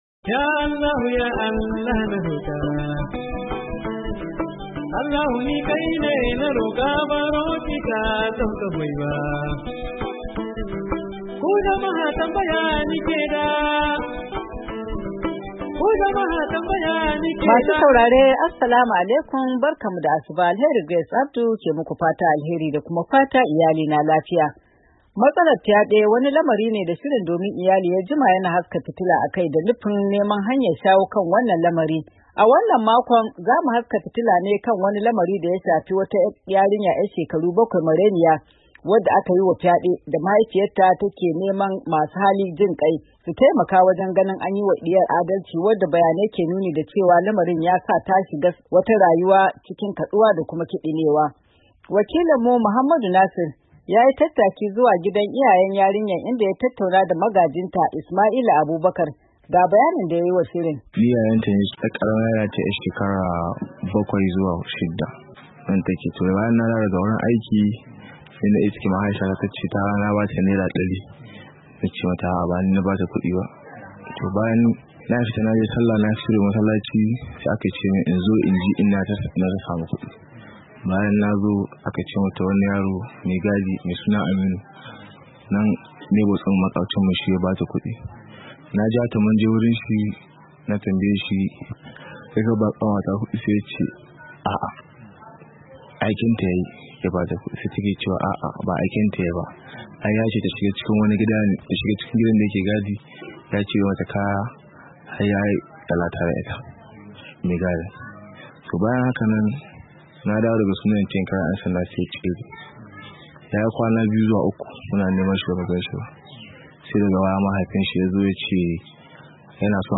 Yanzu haka mahaifiyar wata yarinya ‘yar shekara bakwai da aka yi wa fyade na cikin dawainiyar yawon asibitin kula da lafiyar kwakwalwa dake garin Kware a jihar Sakkwato, domin fyaden yana son gusar da hankalin yarinyar. Saurari hirar shirin Domin Iyali da dangin yarinyar: DOMIN IYALI:Neman...